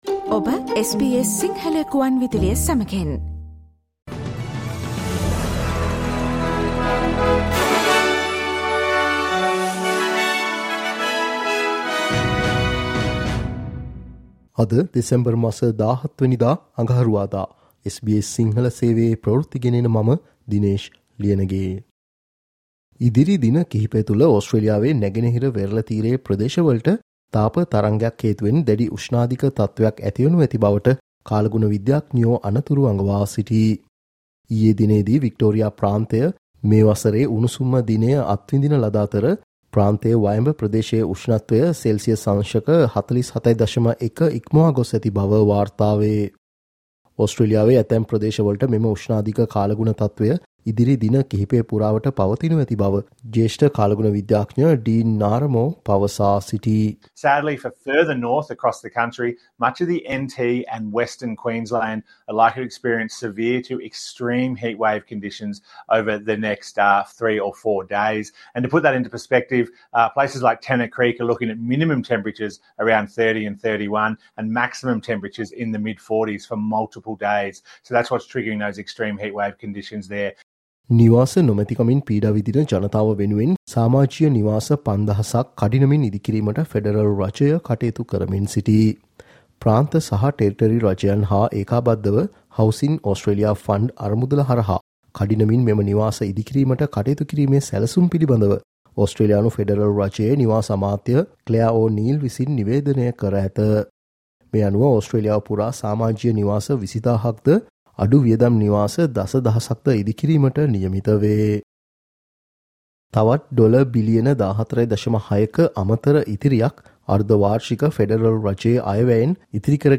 SBS Sinhala Newsflash 17 Dec: Heatwave to hit Australia's east coast in coming days